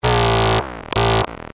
Circuit Bent Sounds " bassy blip
描述：低音和高音的那种声音来自于儿童的电路弯曲玩具
标签： 电路弯曲 低音 电路弯曲 光点
声道立体声